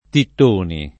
[ titt 1 ni ]